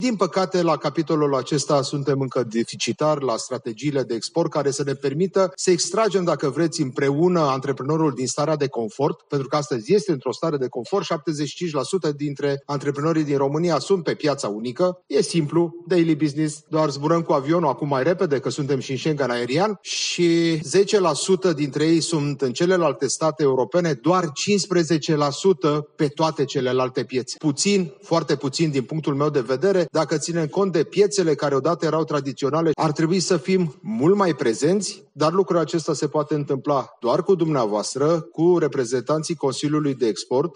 Ministrul economiei a participat, astăzi, la un eveniment care a marcat 20 de ani de activitate a Consiliului de Export al României